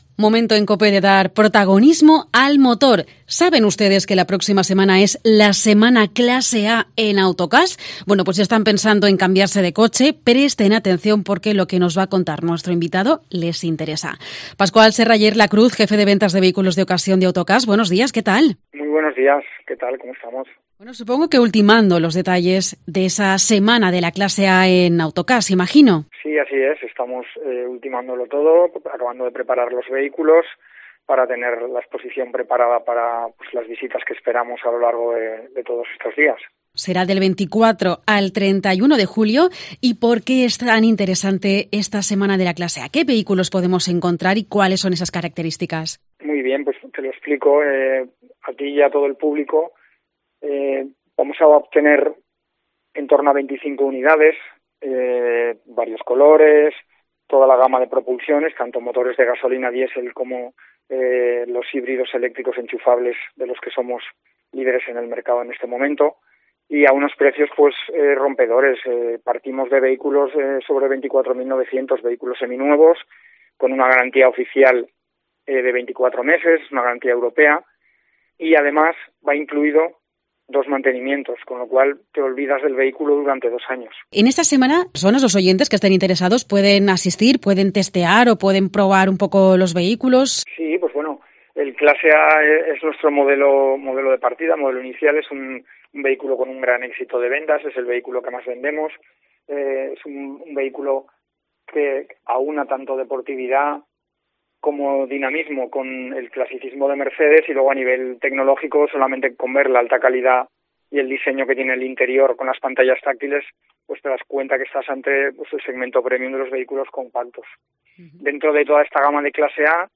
Nos da todos los detalles, en esta entrevista